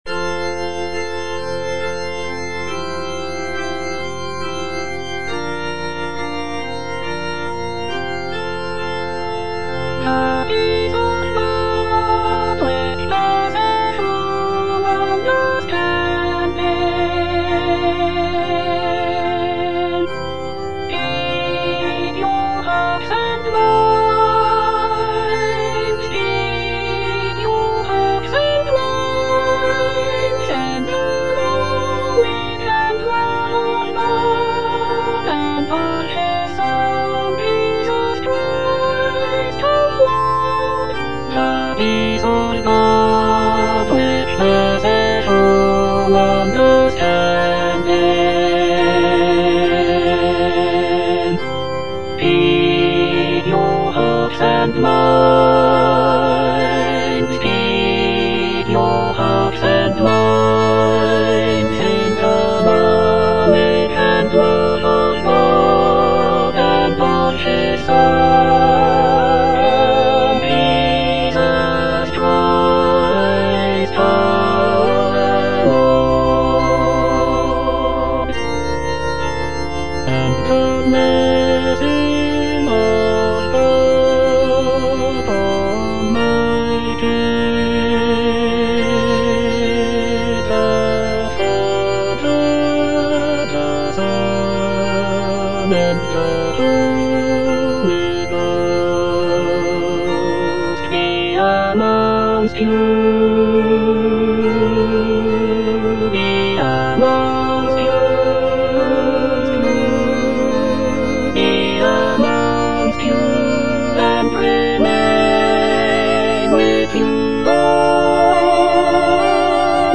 (All voices)